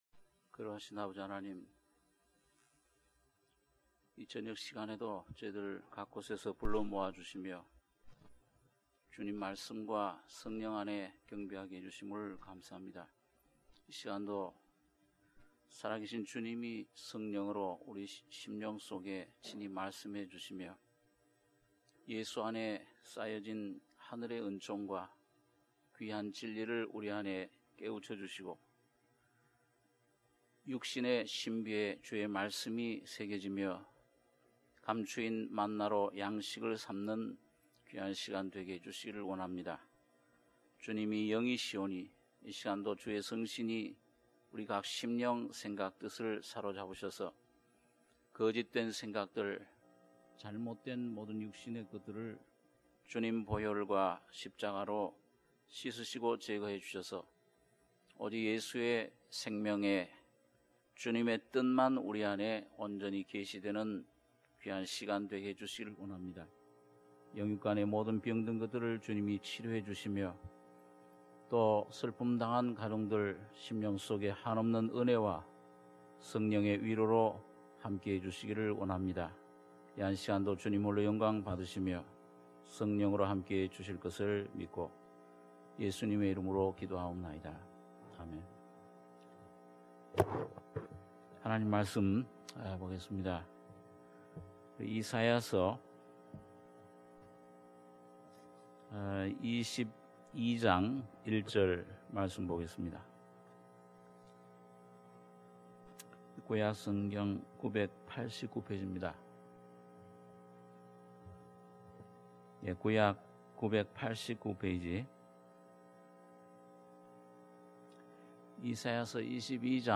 수요예배 - 이사야 22장 1절~11절